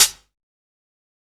Closed Hats
HiHat (13).wav